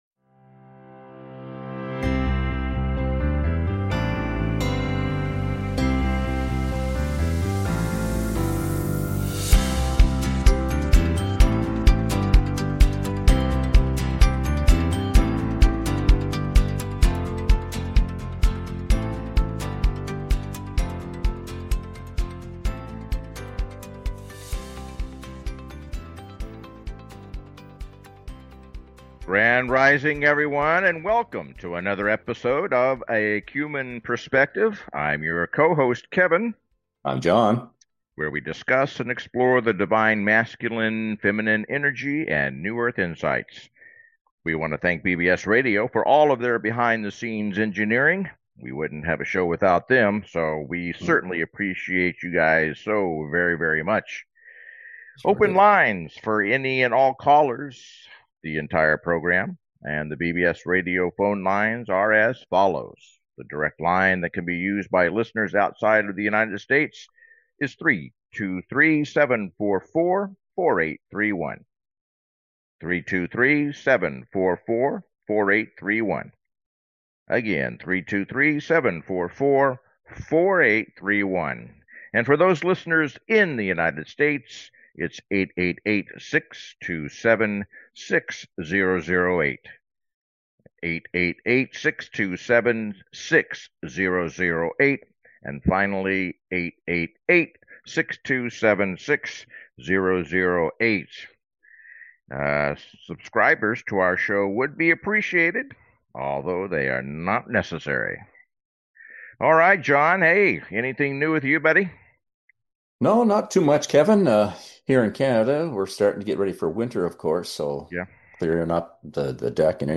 Talk Show Episode, Audio Podcast
The show is structured to welcome call-ins and frequently features special guests, offering a diverse range of perspectives.